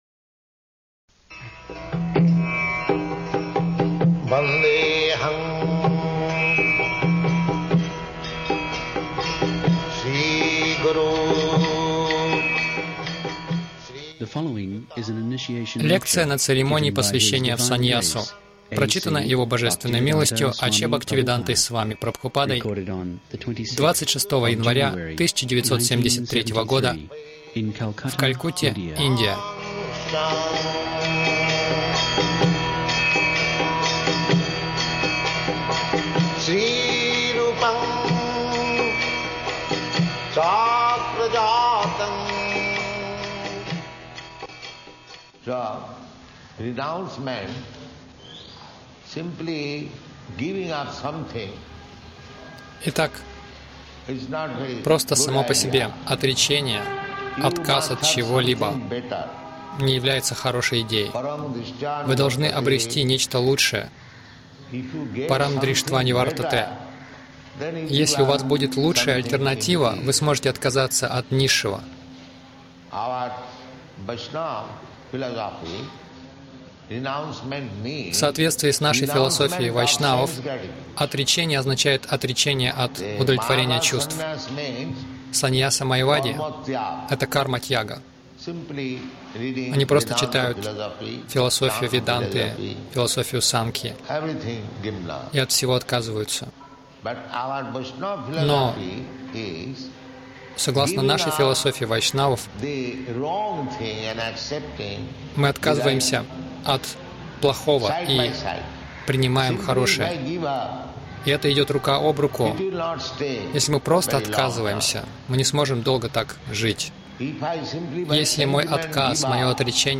Лекция на инициации — Санньяса в Кали югу